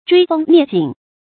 追風躡景 注音： ㄓㄨㄟ ㄈㄥ ㄋㄧㄝ ˋ ㄧㄥˇ 讀音讀法： 意思解釋： 形容速度極快。